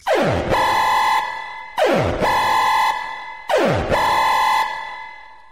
Kategorien: Wecktöne